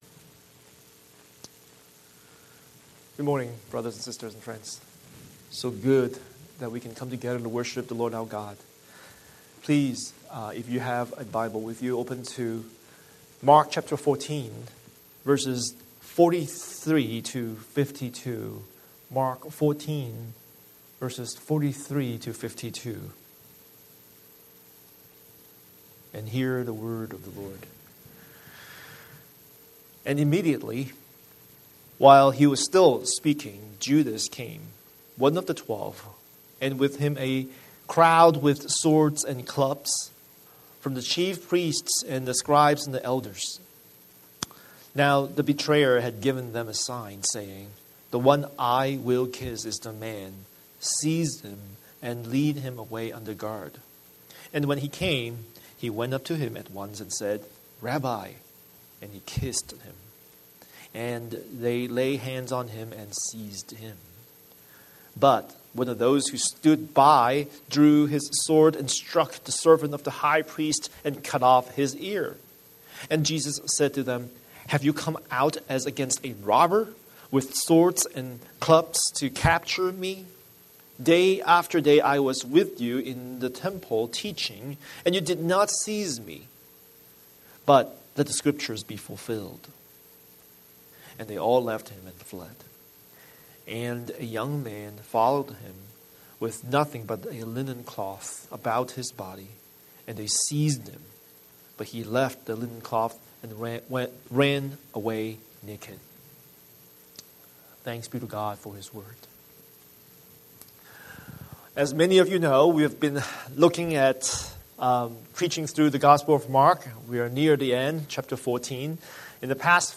Scripture: Mark 14:43-52 Series: Sunday Sermon